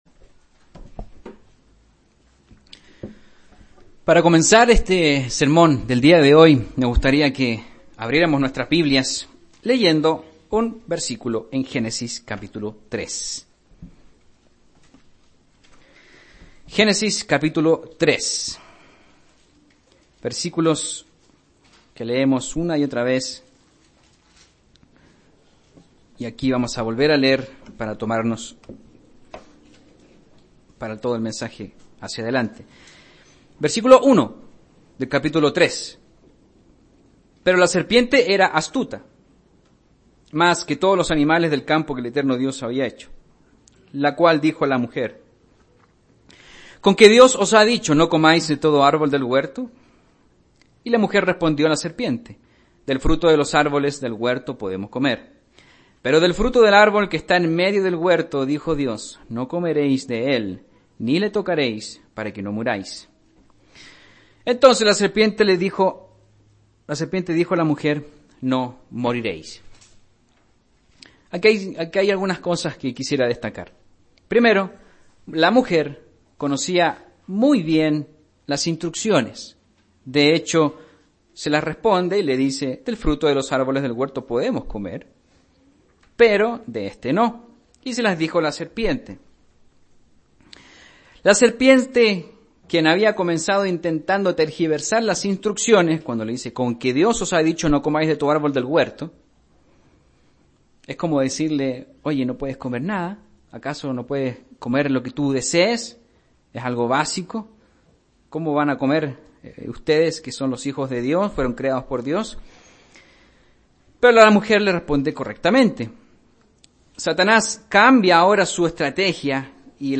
Para comenzar este sermón del día de hoy, me gustaría que abriremos nuestras piblias leyendo un versículo en Génesis capítulo 3.